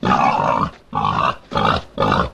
255081e1ee Divergent / mods / Soundscape Overhaul / gamedata / sounds / monsters / flesh / flesh_panic_2.ogg 59 KiB (Stored with Git LFS) Raw History
flesh_panic_2.ogg